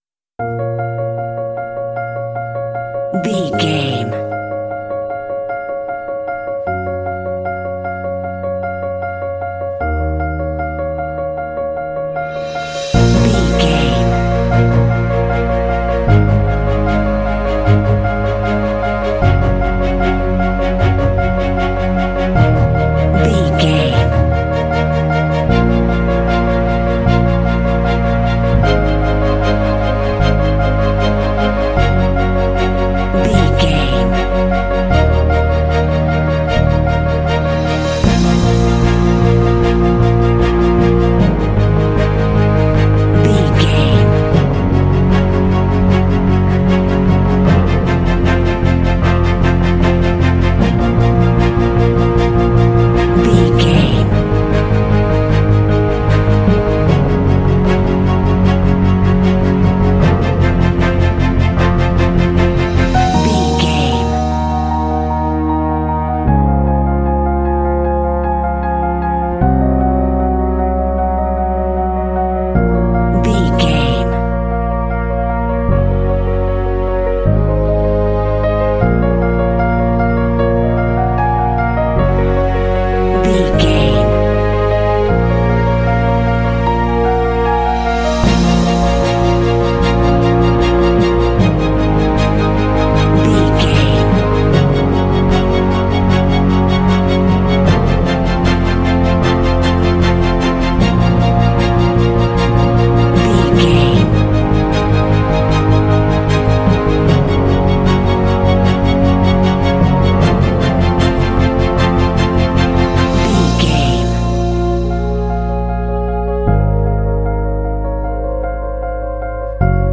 Epic / Action
Fast paced
Aeolian/Minor
B♭
piano
strings
cinematic
classical
underscore